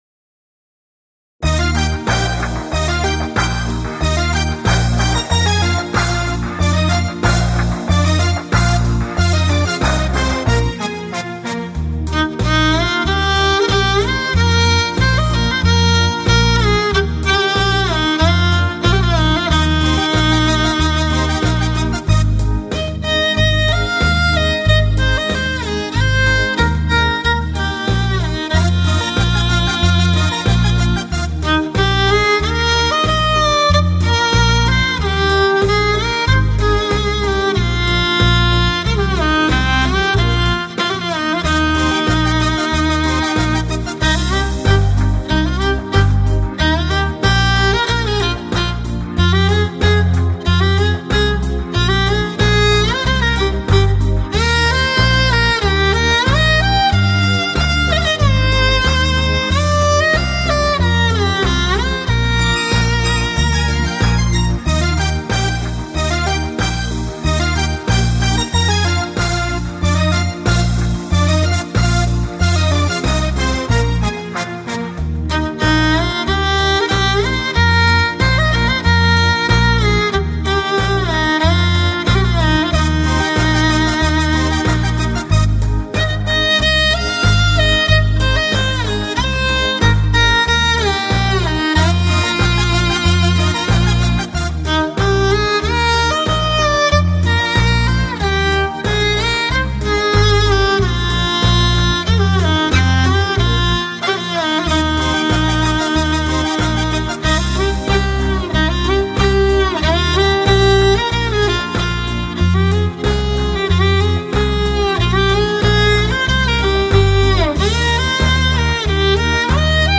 类型: 民乐雅韵
沉粗犷，激昂的特点，体现了蒙古民族的生产、生活和草原风格。